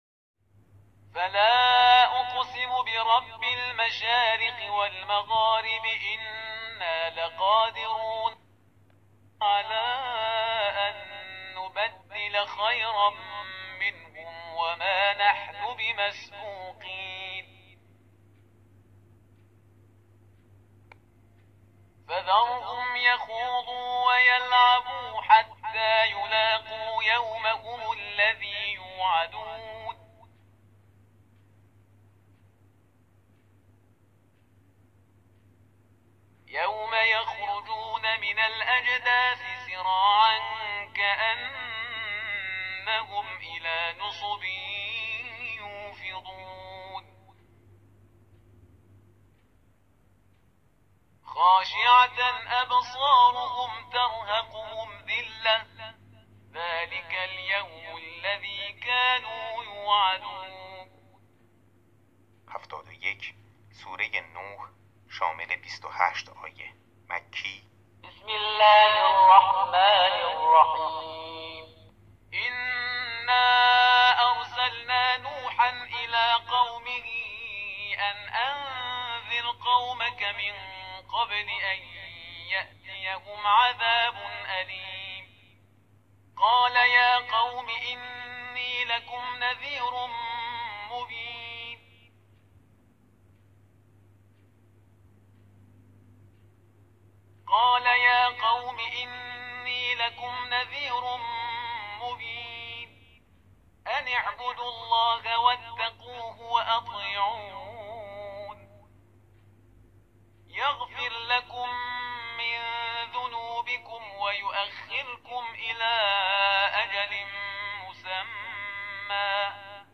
قرائت سوره معارج و نوح